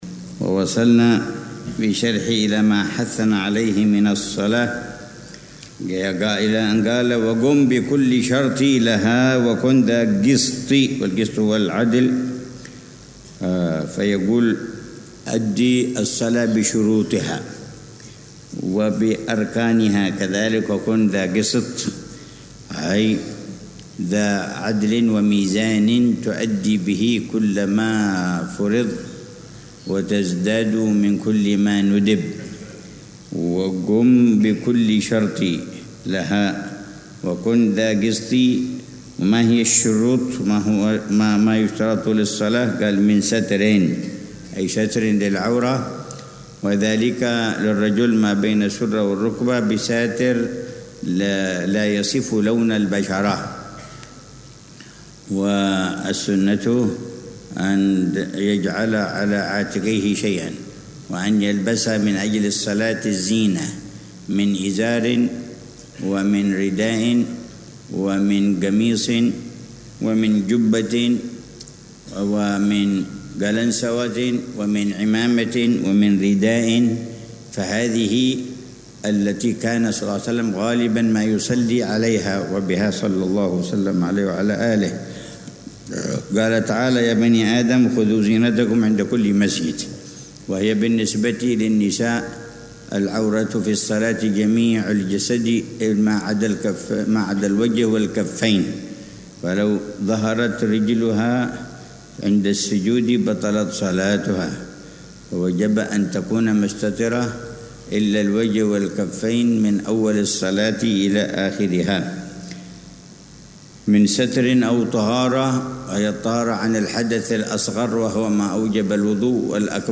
شرح الحبيب عمر بن حفيظ على منظومة «هدية الصديق للأخ والرفيق» للحبيب عبد الله بن حسين بن طاهر. الدرس الثامن عشر ( 5 صفر 1447هـ)